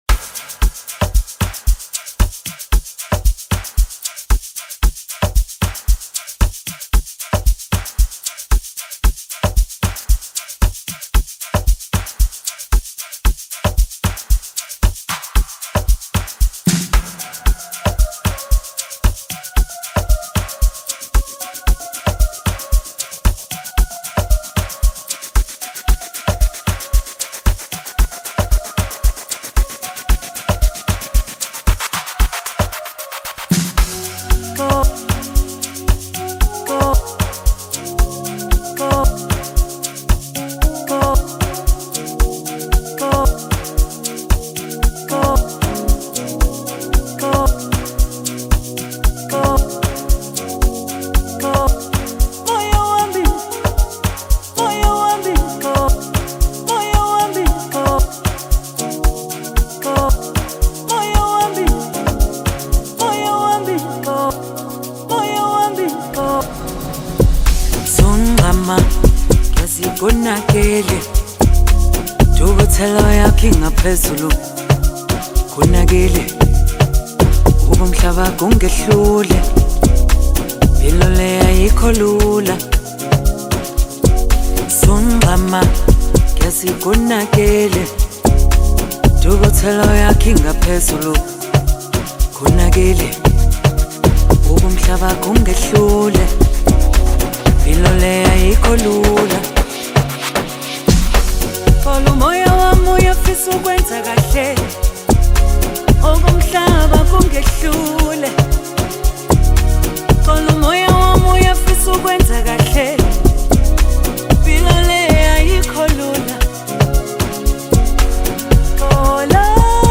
the South African Gospel singer
Afro House production